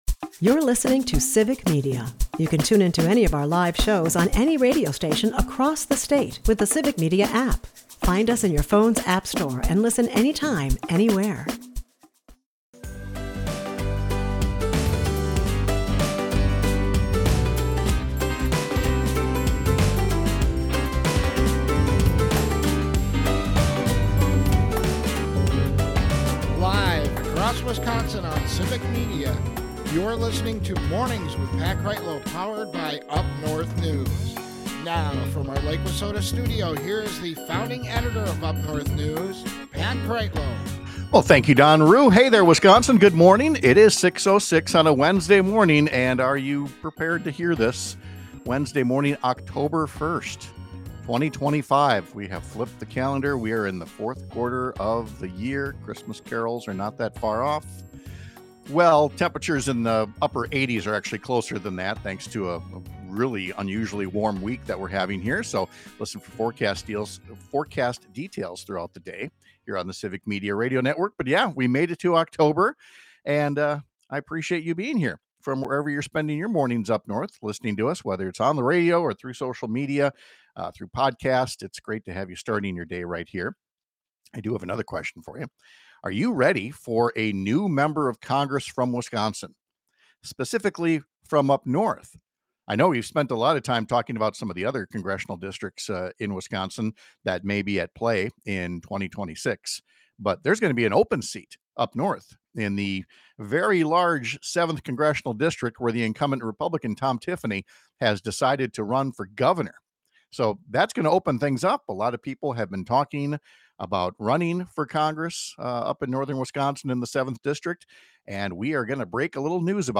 The federal government is in what could be a long shutdown, as wanted by President Trump, who is taking advantage of congressional dysfunction by unilaterally firing thousands of workers. Mornings with Pat Kreitlow is powered by UpNorthNews, and it airs on several stations across the Civic Media radio network, Monday through Friday from 6-9 am.